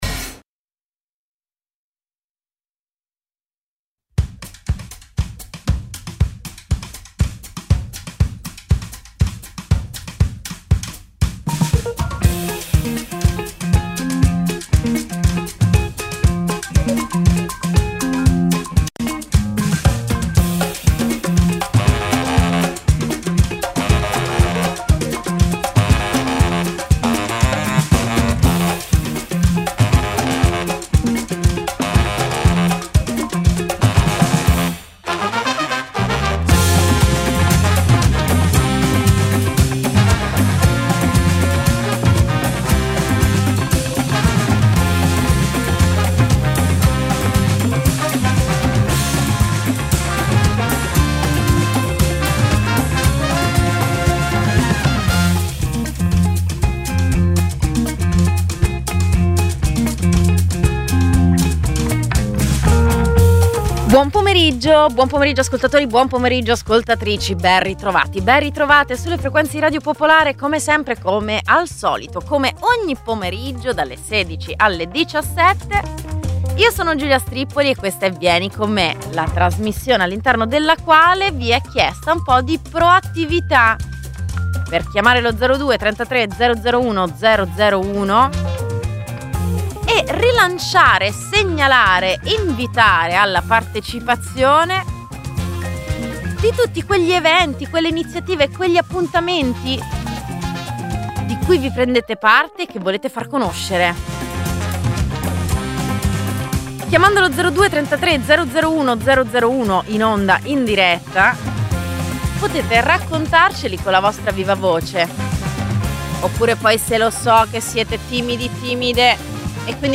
In questa puntata lo studio si popola di ragazzi di studenti del liceo